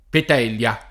vai all'elenco alfabetico delle voci ingrandisci il carattere 100% rimpicciolisci il carattere stampa invia tramite posta elettronica codividi su Facebook Petelia [ pet $ l L a ] o Petilia [ pet & l L a ] top. stor.